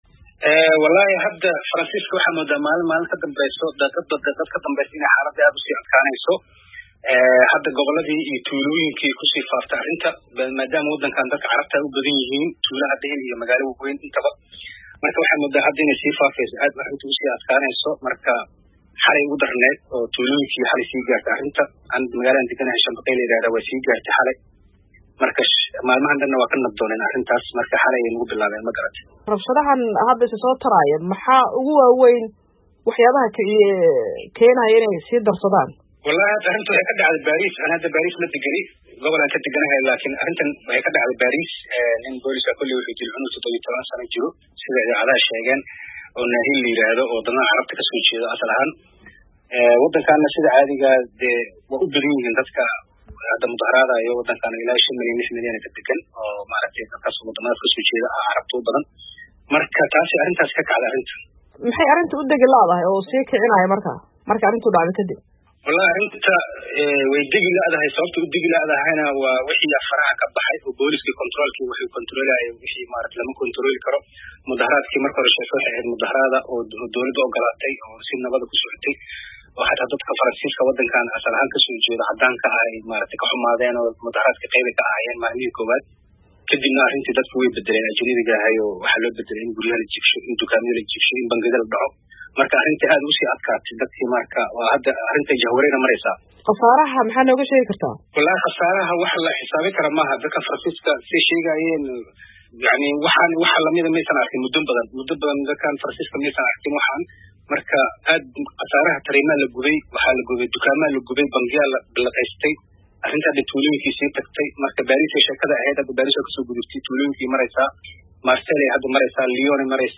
Wareysi: Rabshadaha Faransiiska oo sii socda maalintii shanaad